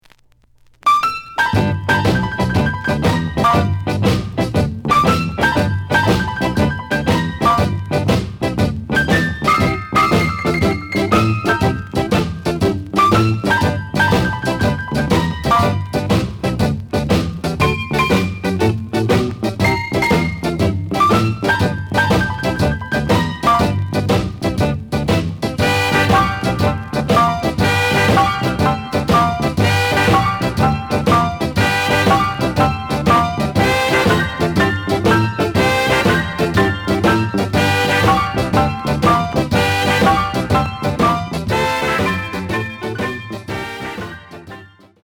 試聴は実際のレコードから録音しています。
The listen sample is recorded from the actual item.
●Genre: Rhythm And Blues / Rock 'n' Roll